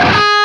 LEAD G#3 LP.wav